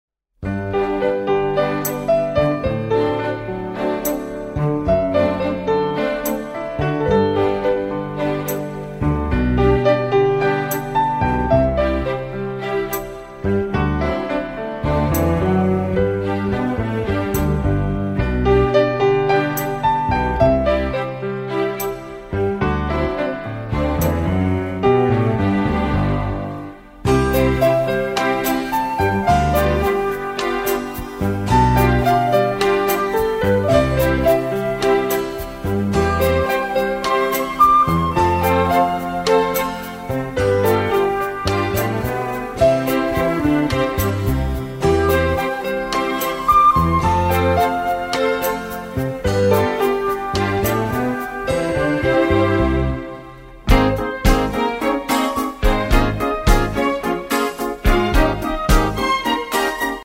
Romantic